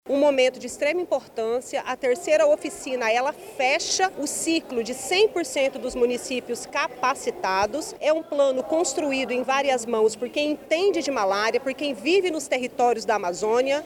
De acordo com a secretária Estadual de Saúde, Nayara Maksoud, a Oficina é o início do processo de erradicação da malária no Amazonas.
SONORA-1-OFICINA-ERRADICACAO-MALARIA-.mp3